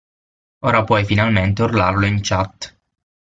/ˈt͡ʃat/